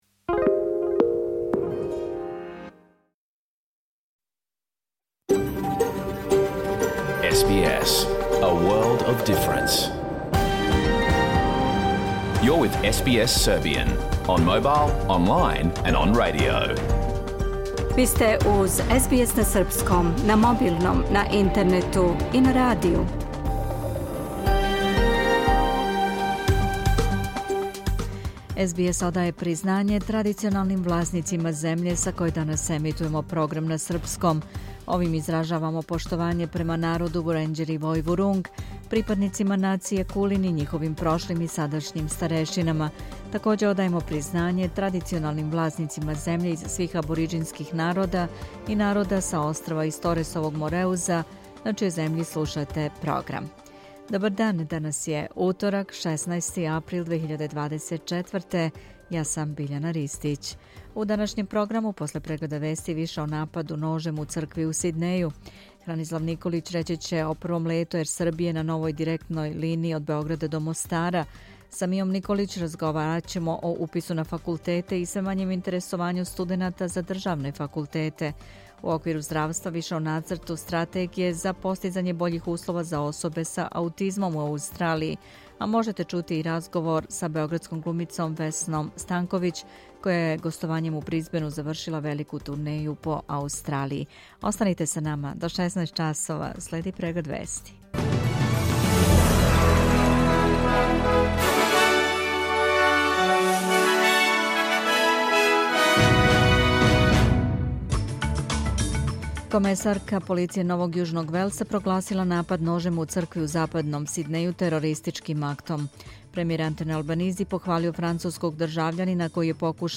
Serbian News Bulletin